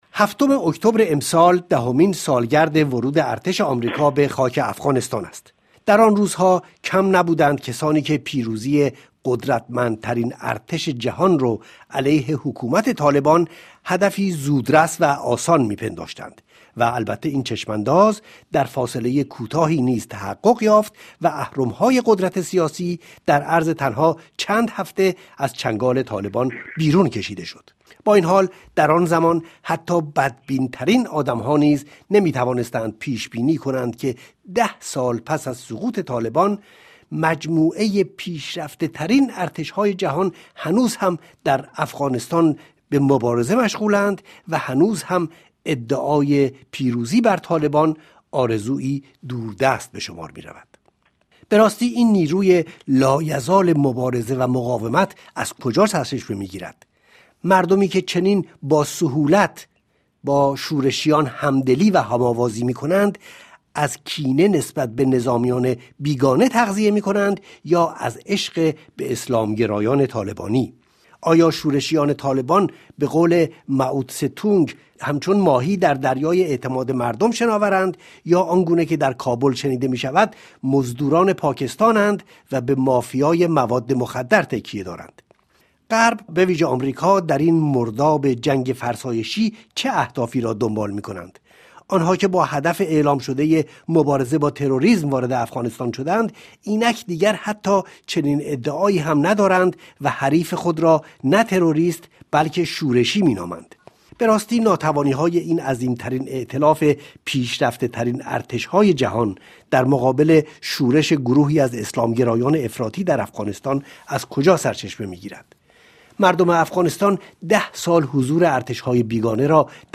Table_Ronde_09_10.mp3